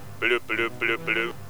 RINGTONE DE CARACOLÓFONO
Quita quita, todavía más, puedes hacer que tu móvil suene como los caracolófonos o denden mushi de la serie.
Ringtone caracolófono